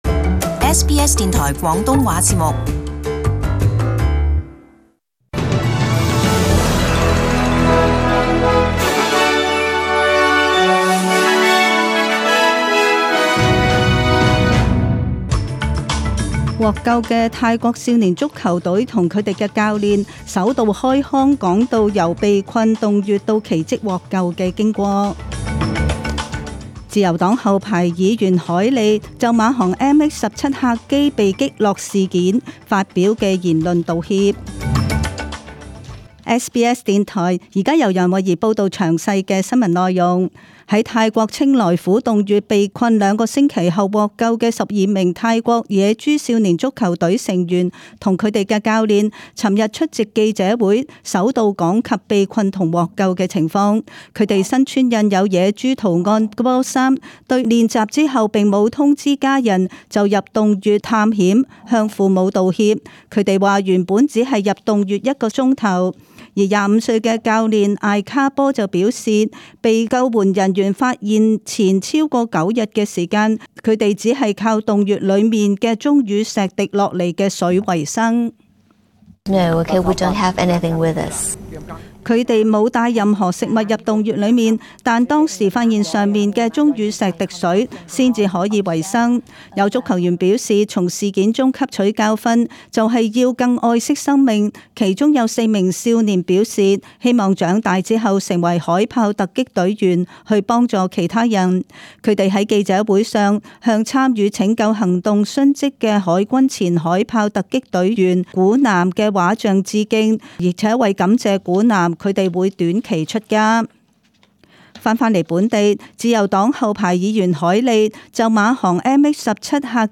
SBS中文新闻 （七月十九日）
请收听本台为大家准备的详尽早晨新闻。